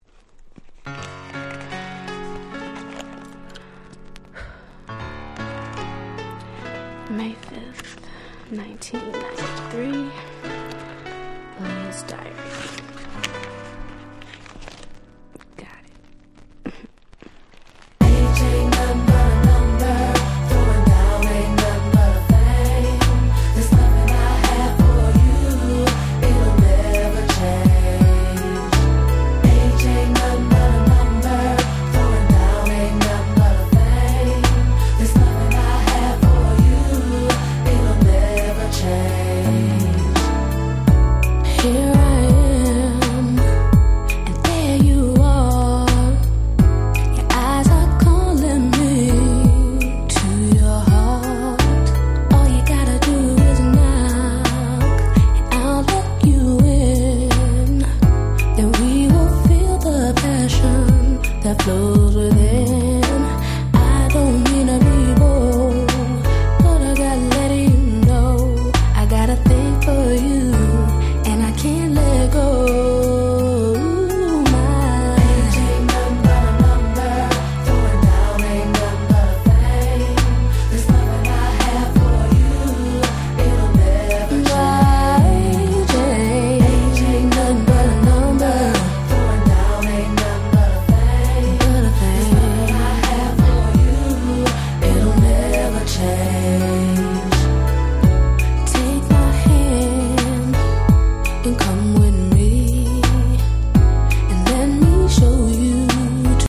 94' Smash Hit R&B !!
哀愁エロエロMidで最高、言う事無しです。
90's ヒップホップソウル